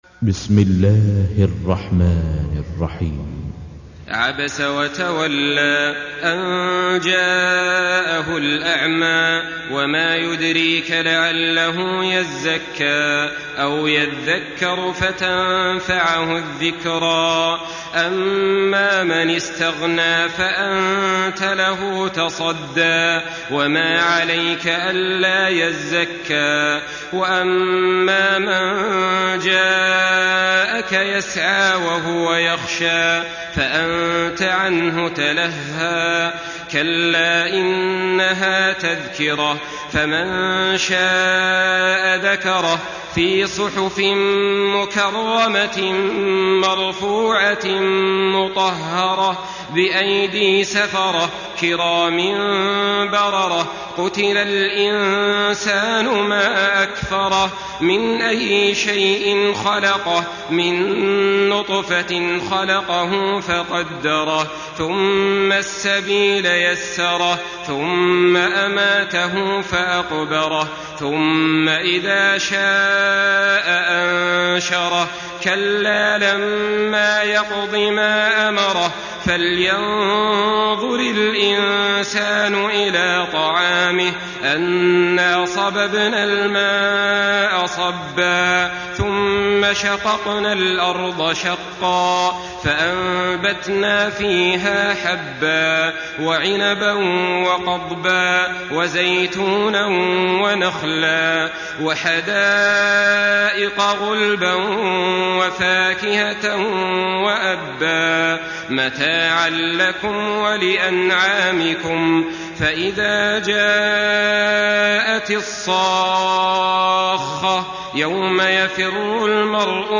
Surah Abese MP3 by Saleh Al-Talib in Hafs An Asim narration.
Murattal Hafs An Asim